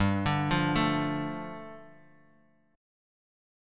G7sus2 chord